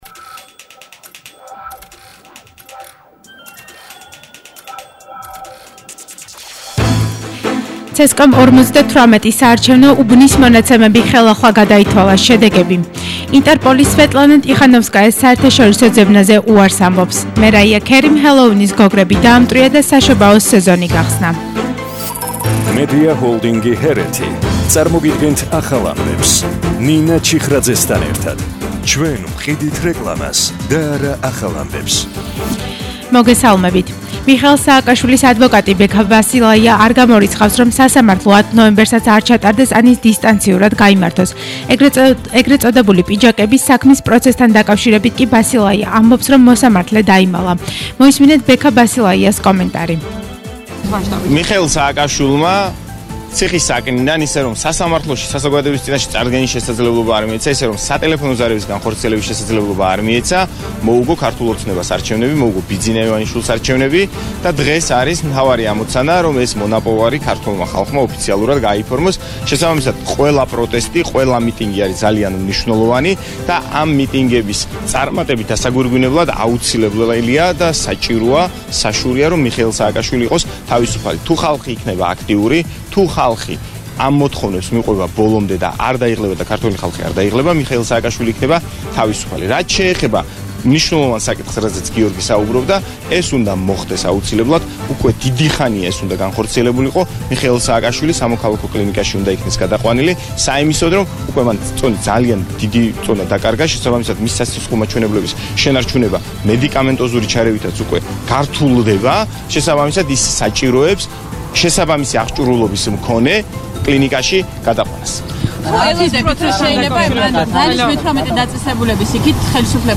ახალი ამბები 14:00 საათზე –3/11/21 – HeretiFM